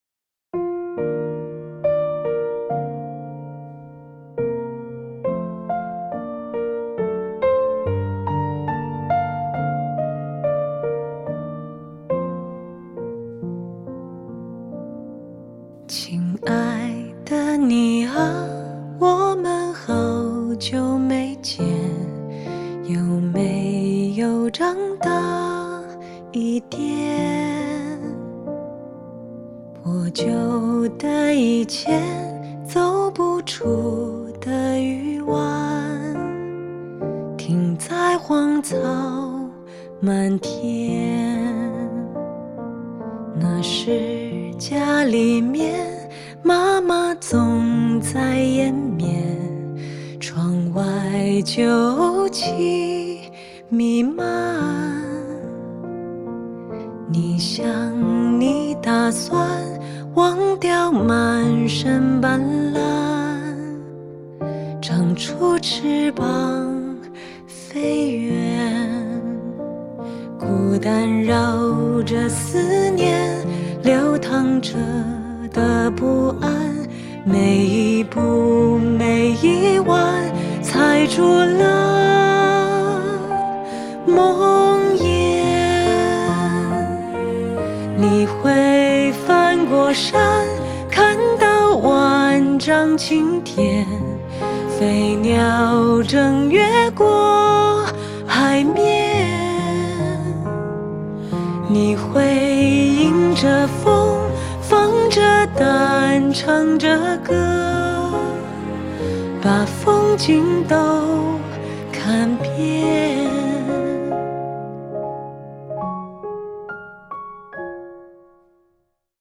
【翻唱】